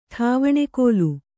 ♪ ṭhāvaṇekōlu